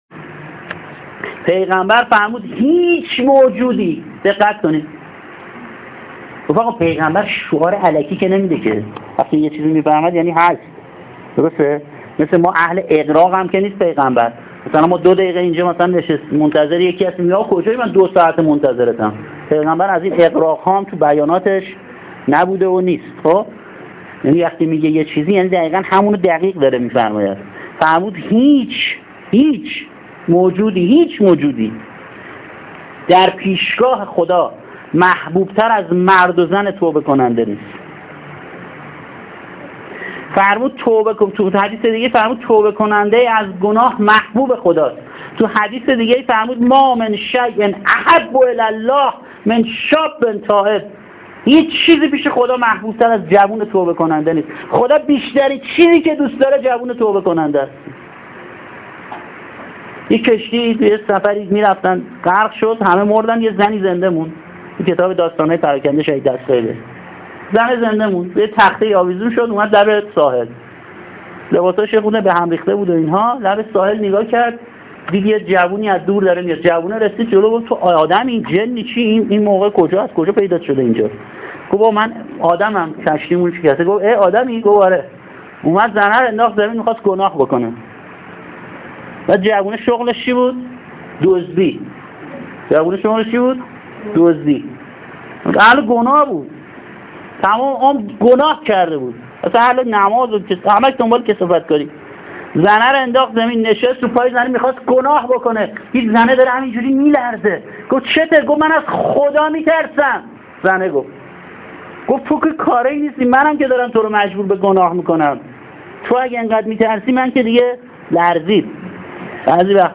گزیده ای از مبحث هیأت متوسلین به جواد الائمه علیه‌السلام شب نوزدهم ماه مبارک رمضان